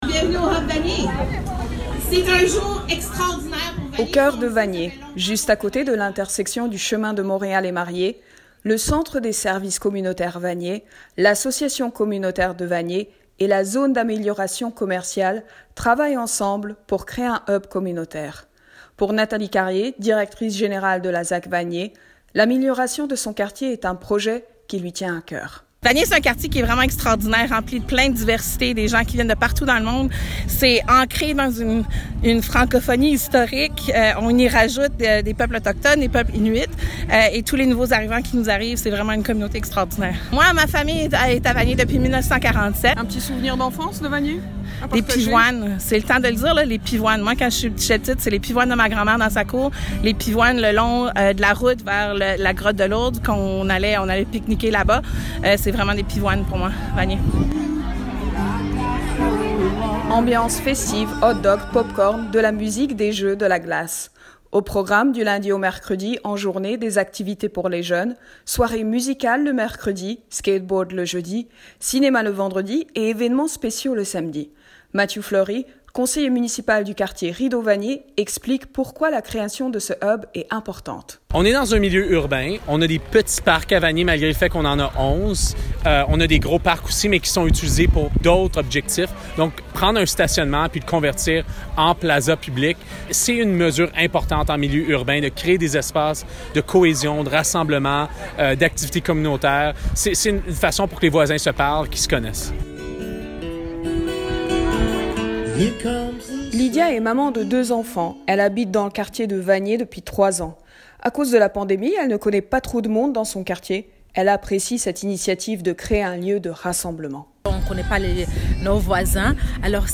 Reportage-HUB-Vanier_mixage-final_IJL.mp3